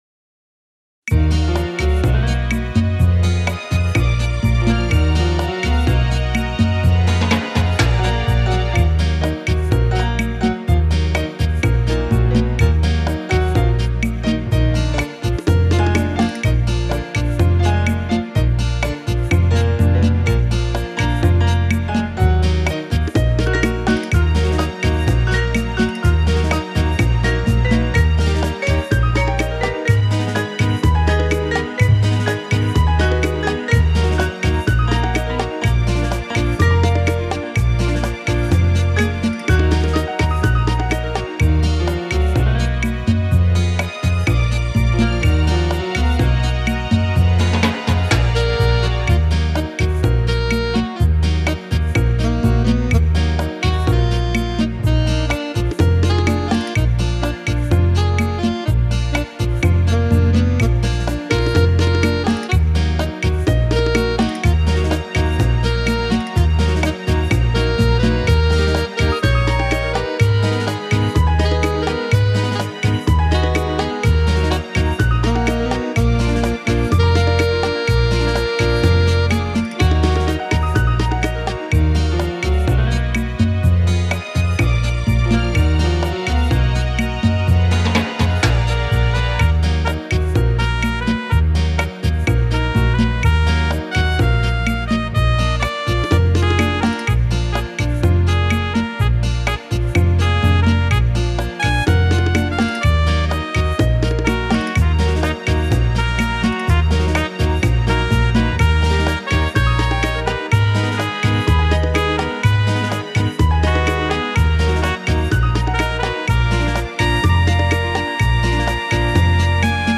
Это просто из опыта освоения синтезатора...